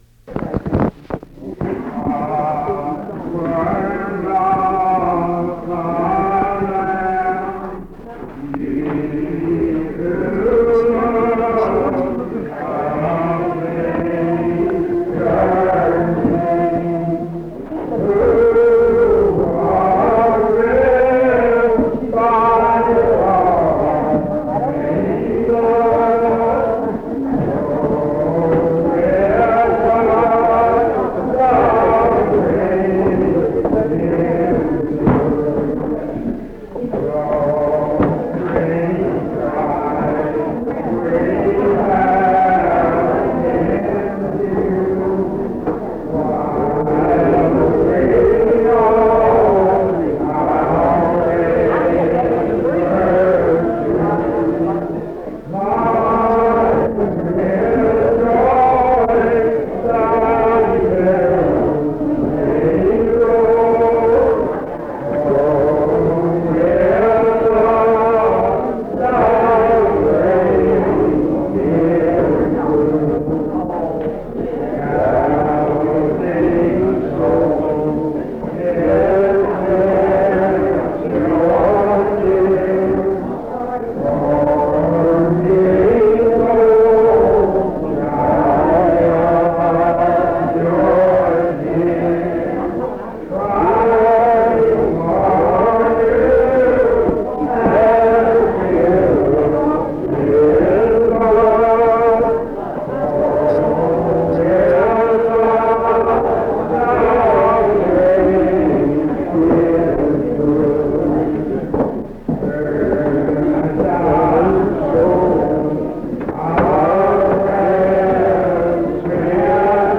Sermon
at an unknown service in the afternoon